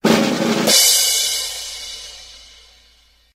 Song: SFX - 12896 Harri Circus Short